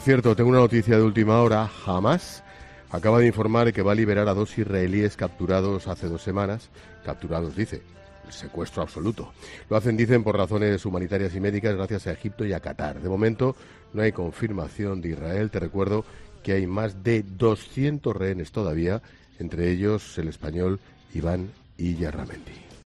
Te da más detalles el director de 'La Linterna', Ángel Expósito